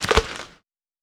Generic Net Hit Normal.wav